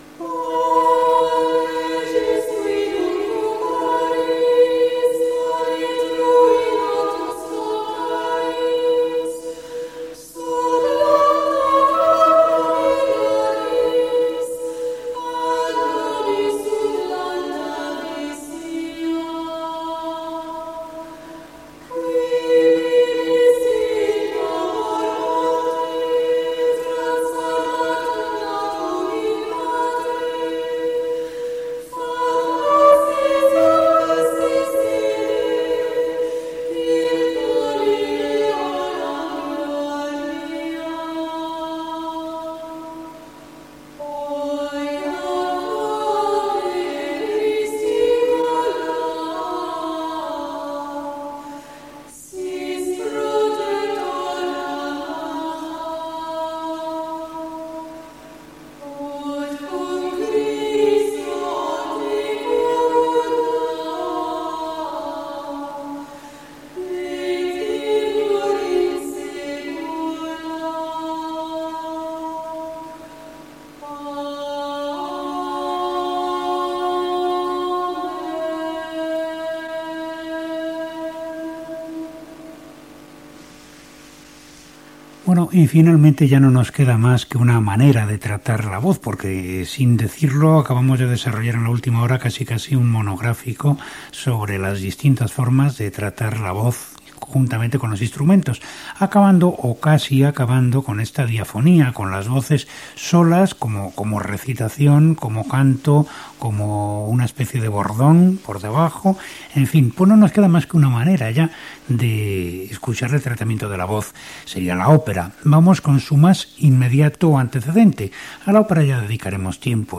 Peça musical i comentari sobre el tractament de la veu a la música i presentació d'un tema antecedent de l'òpera
Musical